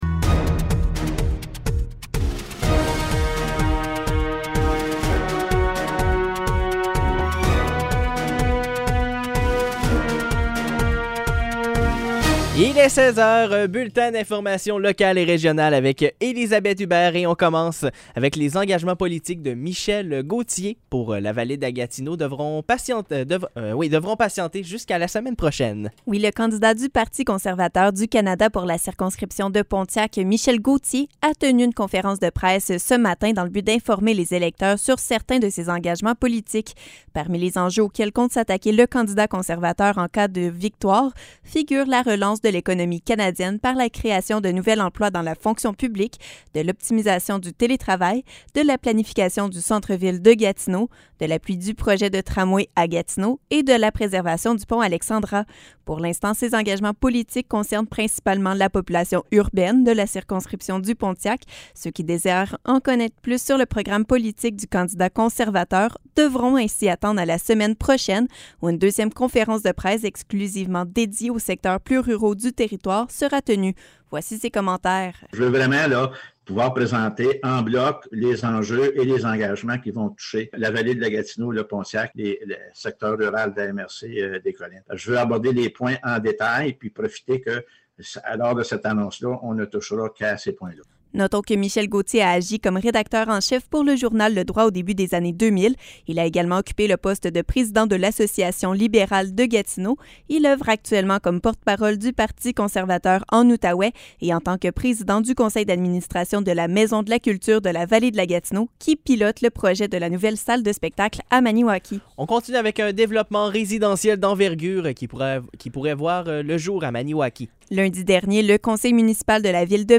Nouvelles locales - 18 août 2021 - 16 h